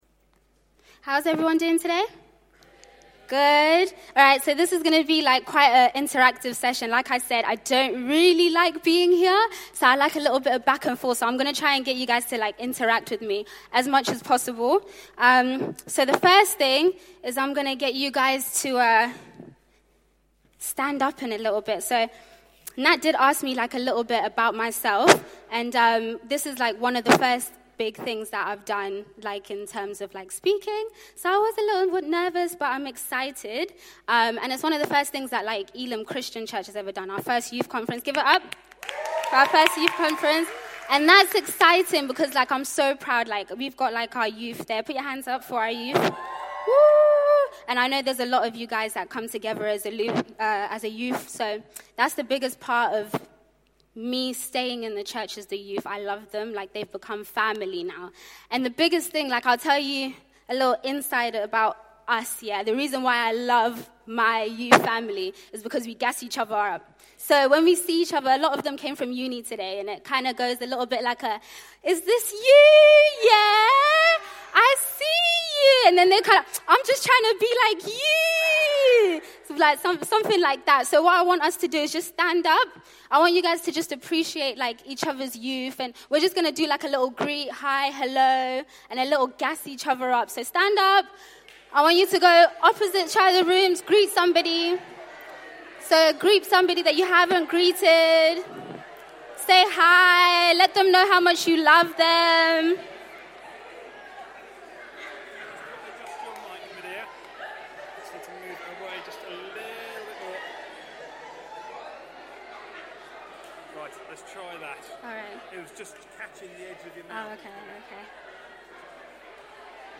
speaks on 'I Am' at the Alive 2019 Youth Conference, looking at Romans 8.